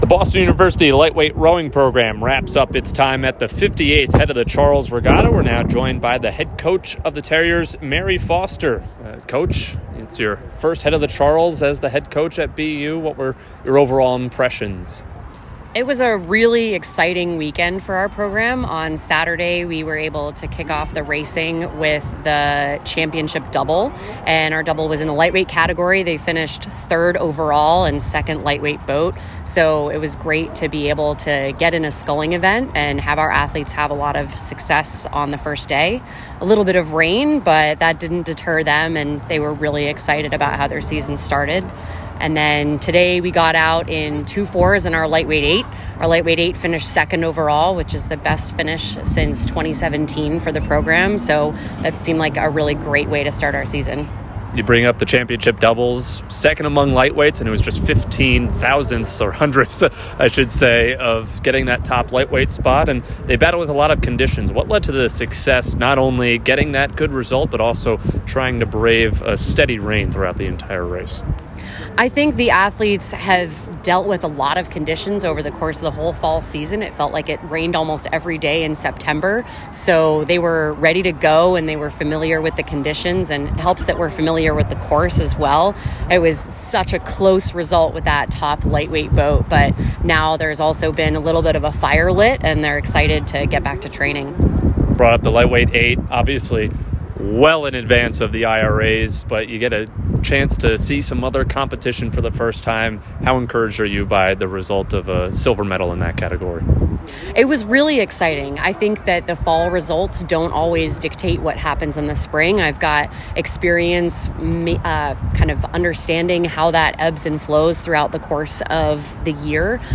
LTROW_HOCR_Postrace.mp3